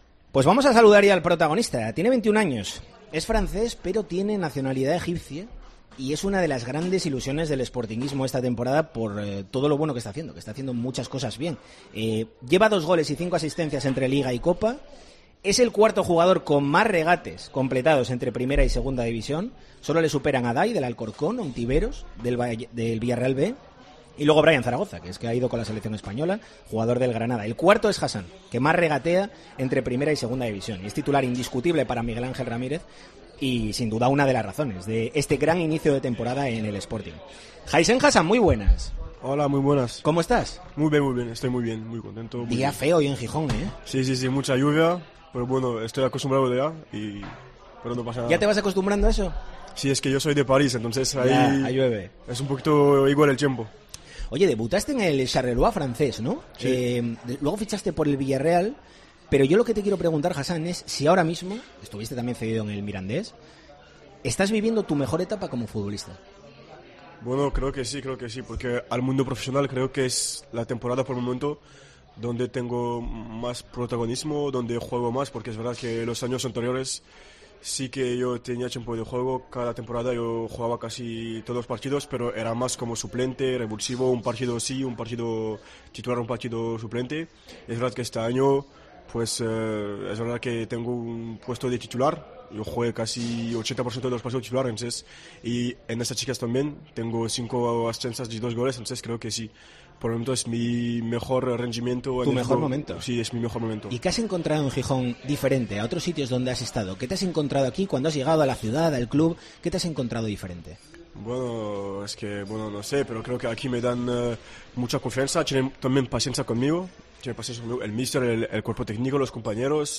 entrevista en exclusiva